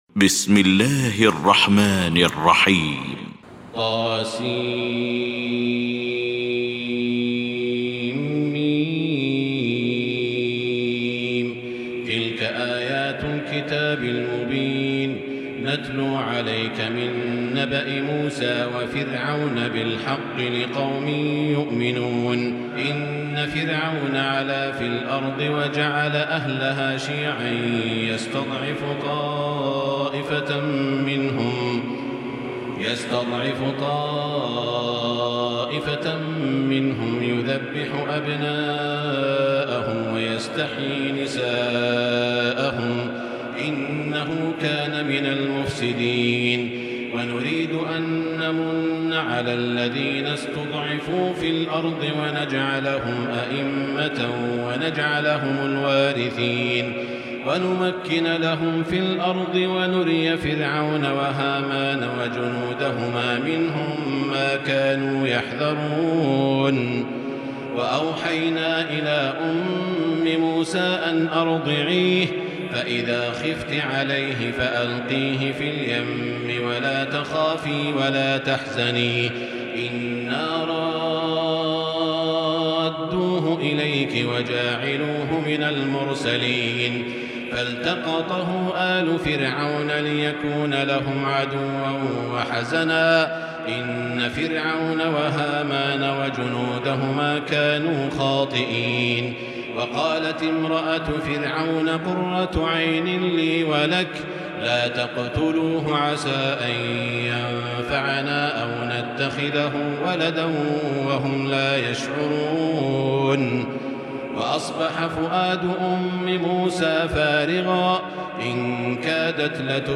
المكان: المسجد الحرام الشيخ: سعود الشريم سعود الشريم معالي الشيخ أ.د. عبدالرحمن بن عبدالعزيز السديس القصص